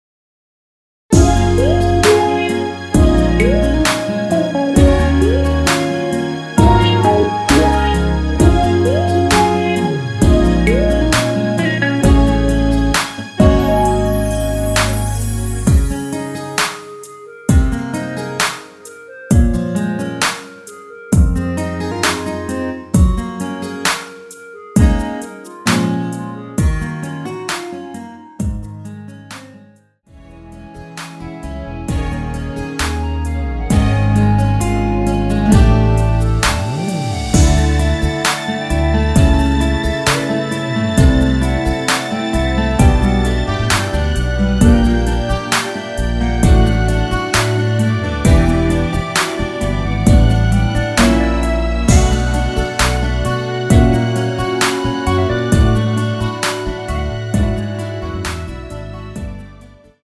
원키에서(+1)올린 멜로디 포함된 MR입니다.
Db
멜로디 MR이라고 합니다.
앞부분30초, 뒷부분30초씩 편집해서 올려 드리고 있습니다.
중간에 음이 끈어지고 다시 나오는 이유는